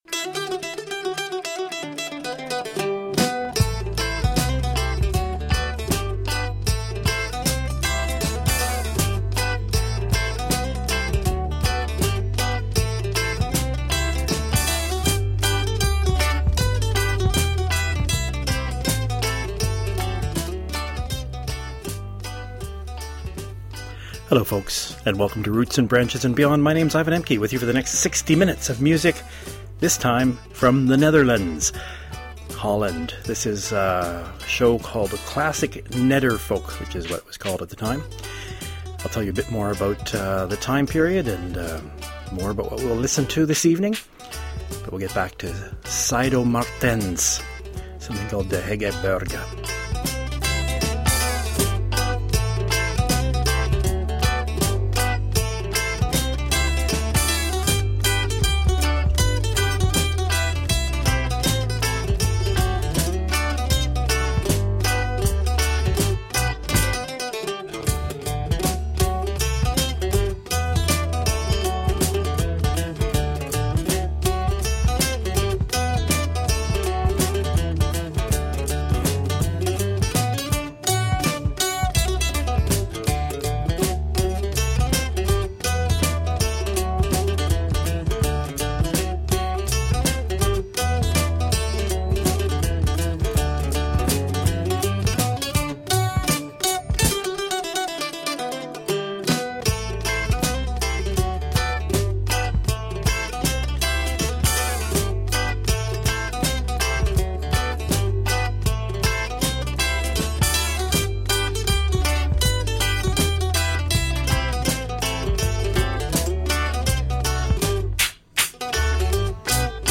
Early folk-rock from the Netherlands